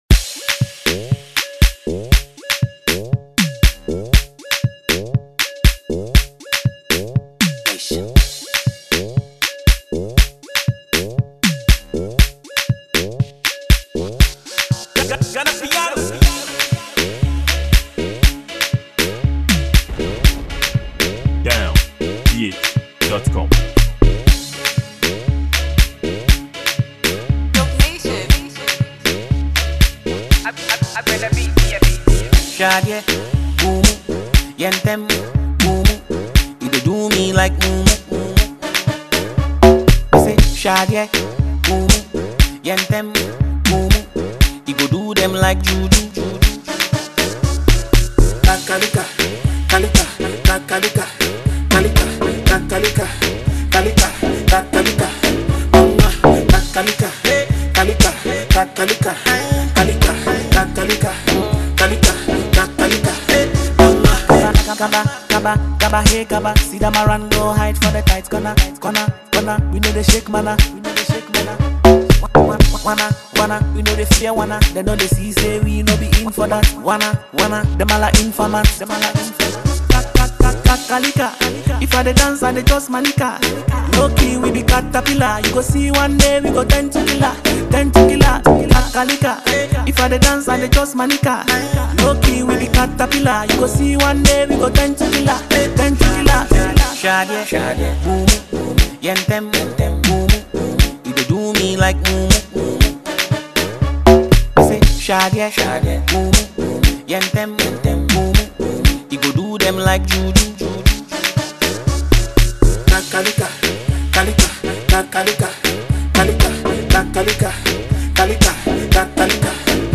Music duo and the only twins in the Ghanaian music industry
Genre: Afrobeat